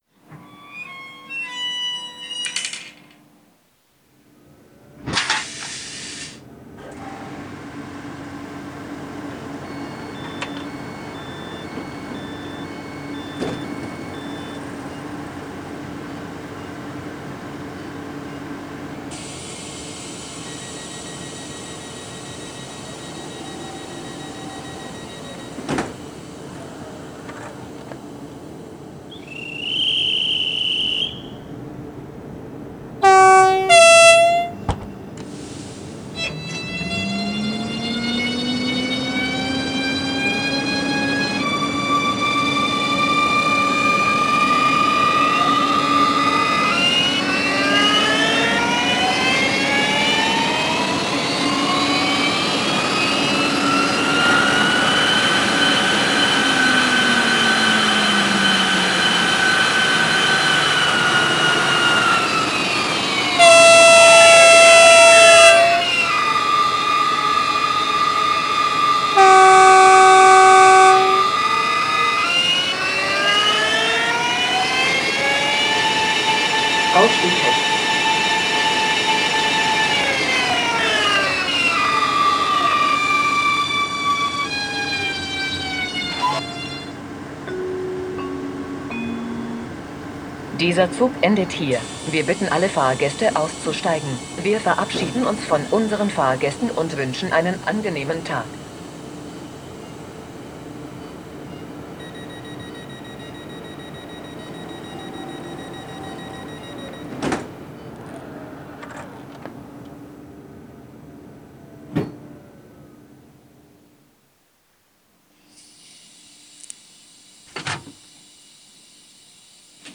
DB Baureihe 462 DB Baureihe 1462 (Siemens Desiro HC) elektro